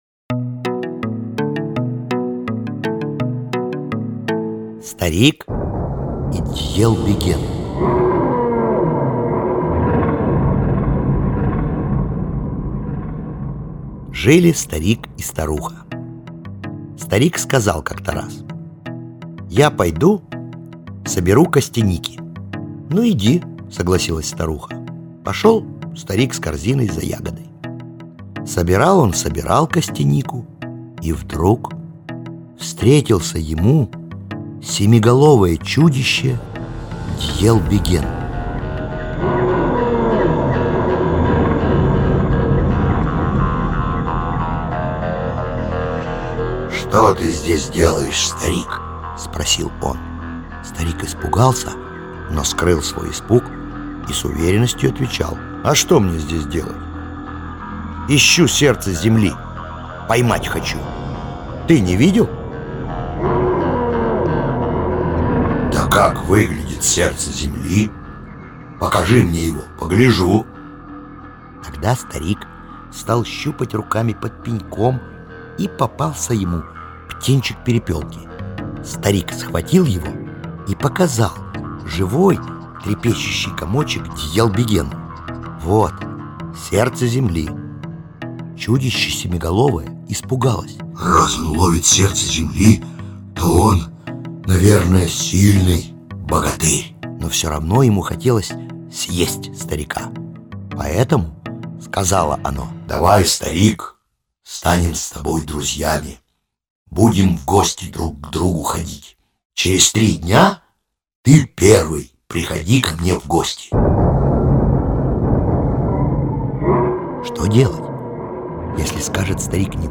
Старик и Дьелбеген - алтайская аудиосказка - слушать онлайн